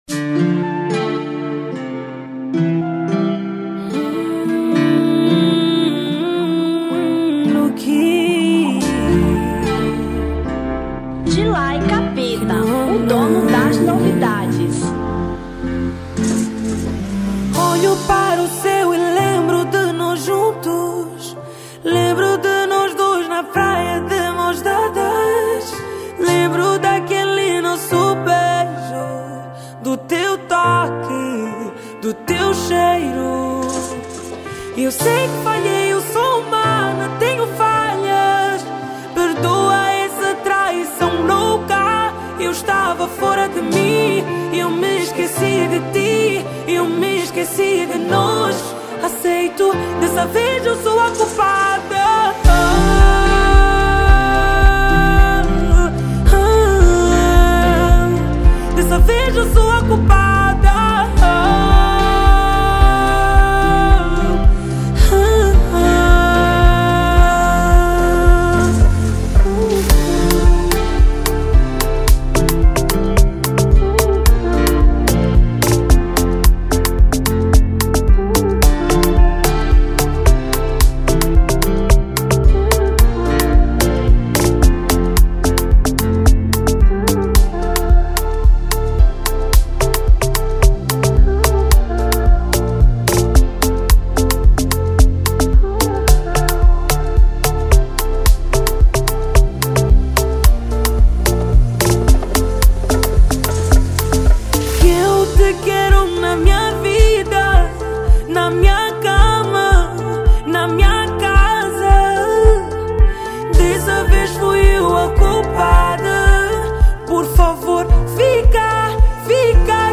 Afro Beat 2025